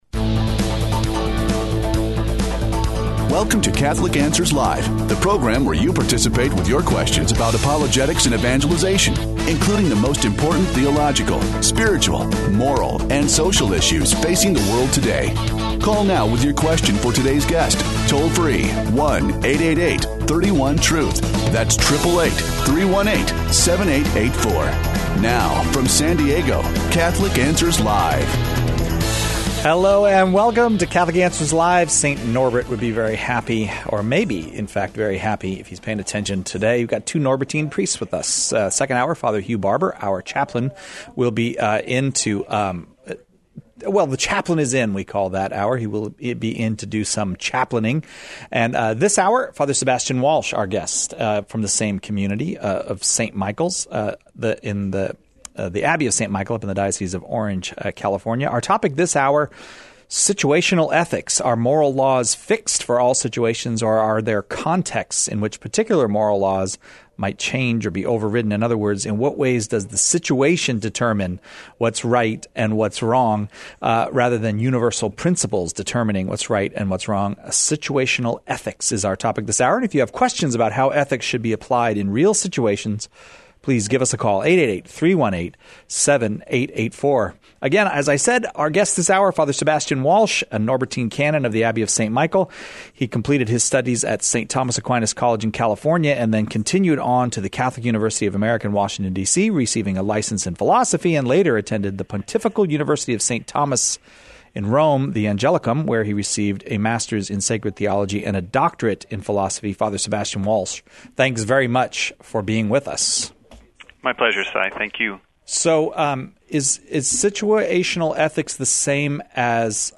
takes caller questions on what is true and what is false in situational ethics.